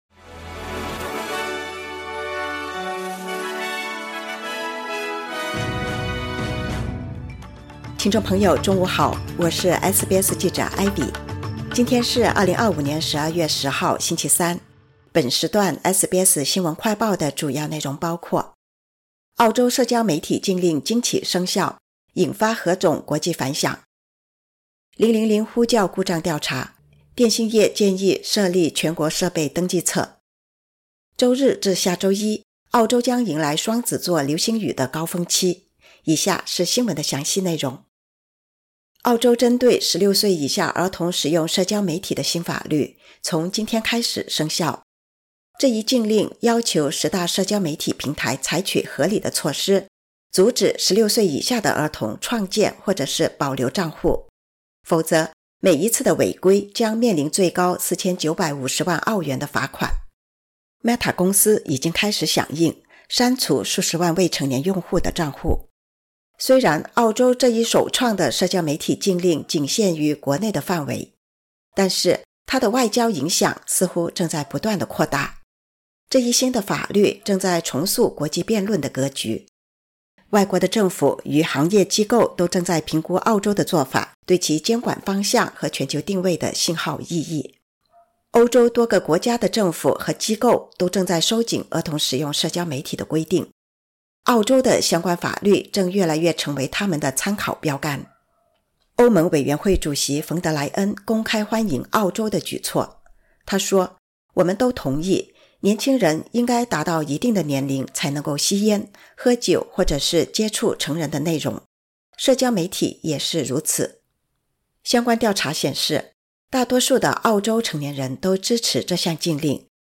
【SBS新闻快报】保护vs一刀切：澳洲社媒禁令生效 引发何种国际反响？